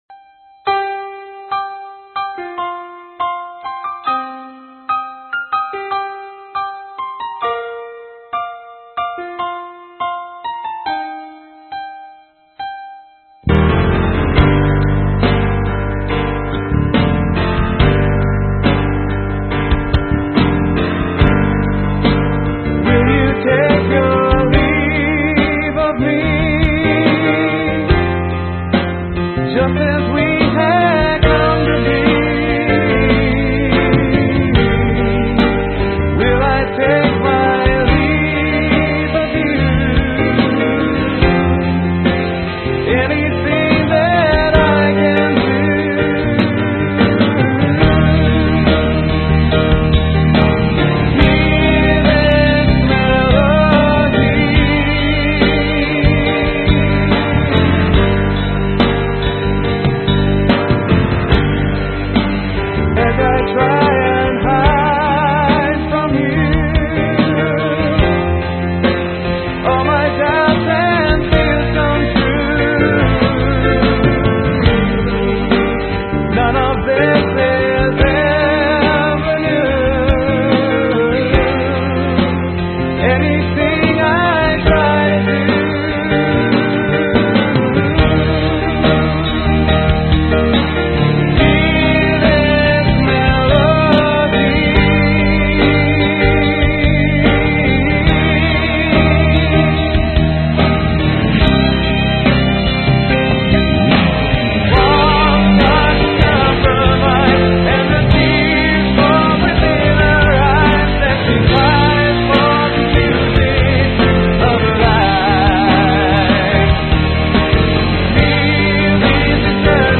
Style: Contemporary Christian